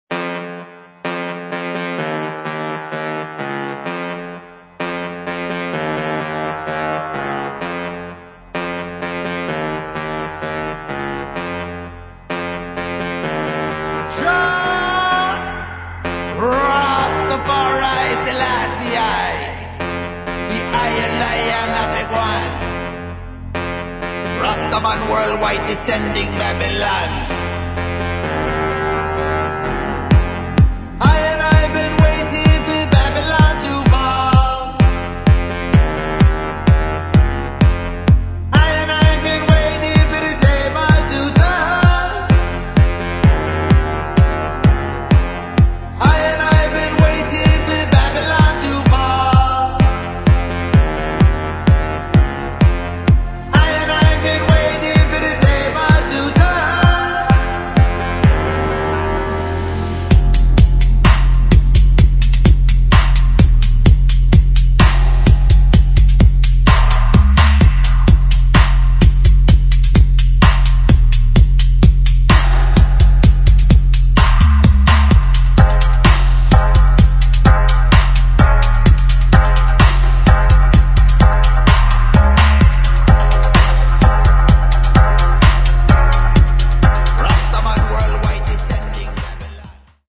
[ DUB / DUBSTEP ]